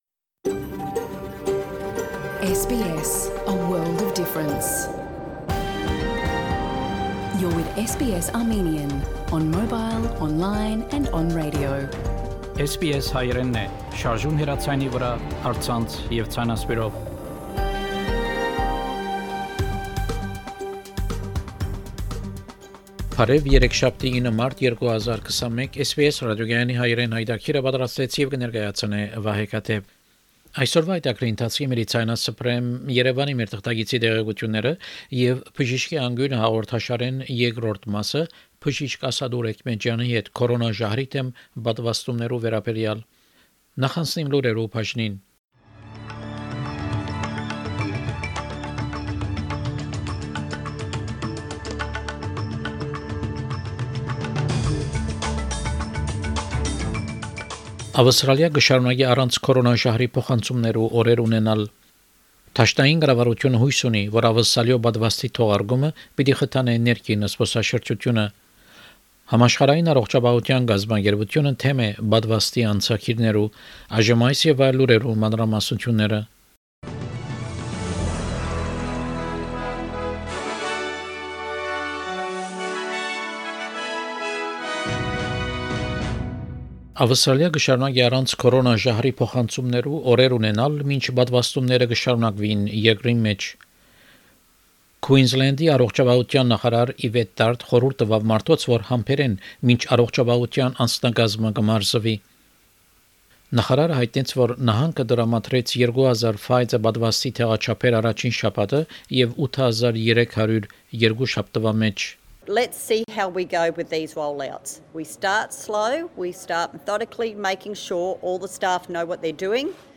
SBS Armenian news bulletin – 9 March 2021
SBS Armenian news bulletin from 9 March 2021 program.